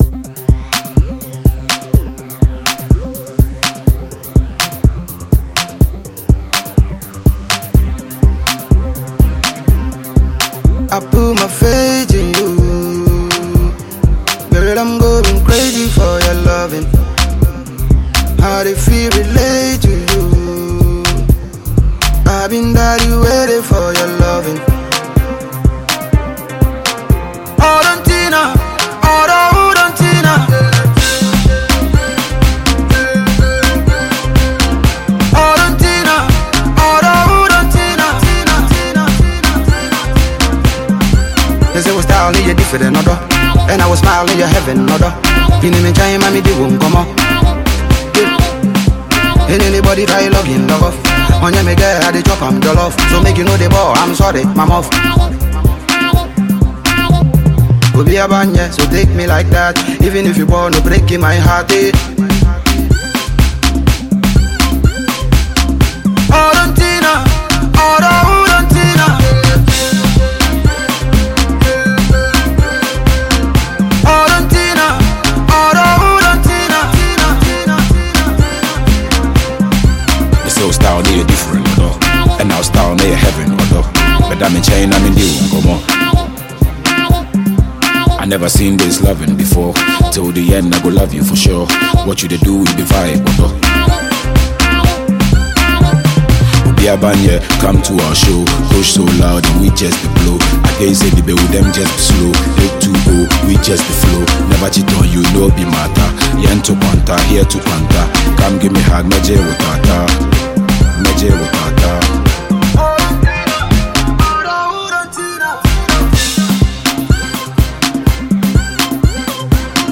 Ghanaian multiple award-winning duo